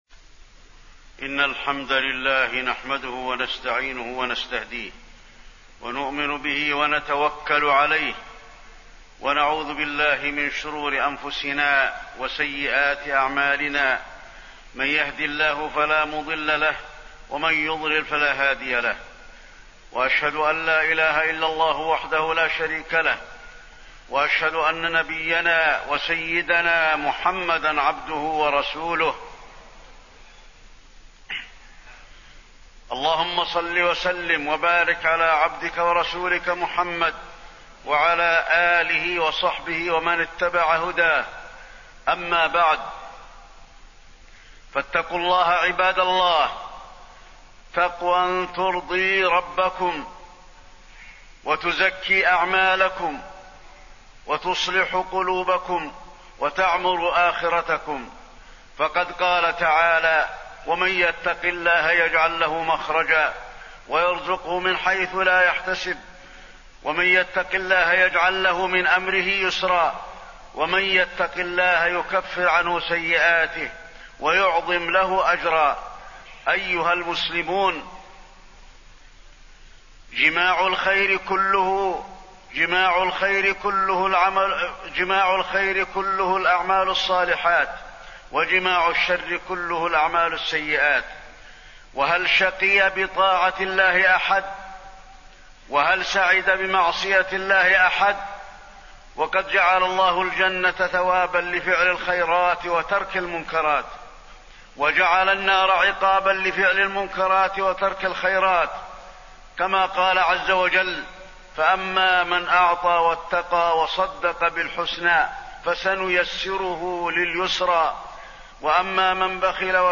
تاريخ النشر ٣ جمادى الآخرة ١٤٣٢ هـ المكان: المسجد النبوي الشيخ: فضيلة الشيخ د. علي بن عبدالرحمن الحذيفي فضيلة الشيخ د. علي بن عبدالرحمن الحذيفي فضائل الأعمال الصالحة